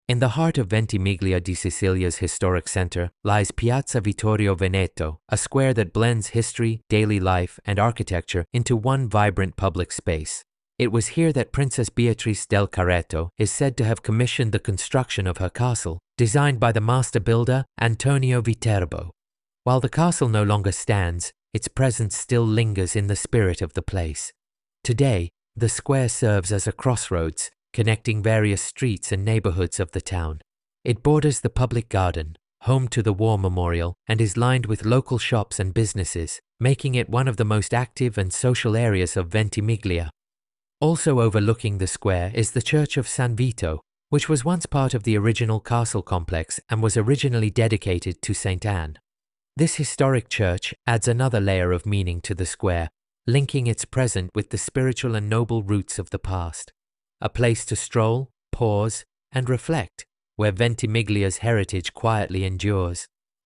Audio Guida